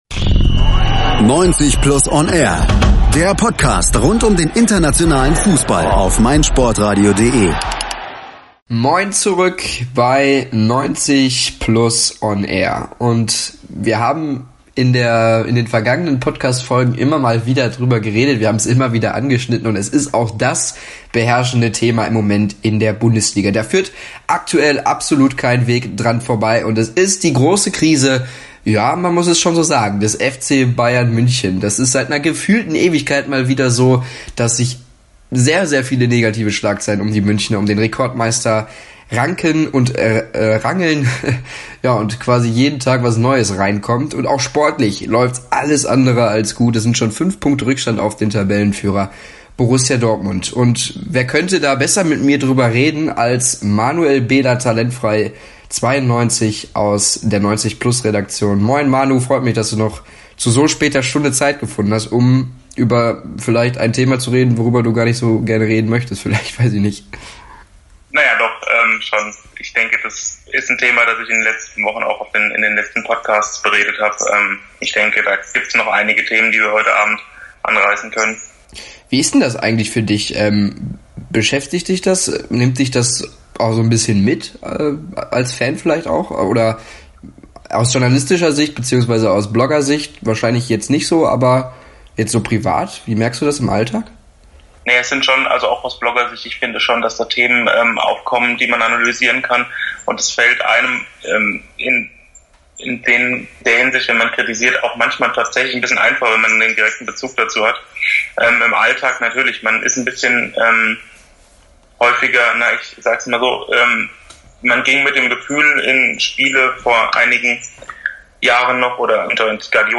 In der Diskussion versuche beide der Wurzel der Probleme auf den Grund zu kommen.